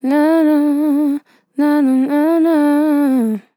Categories: Vocals Tags: dry, english, female, fill, LOFI VIBES, NA, NAHH, sample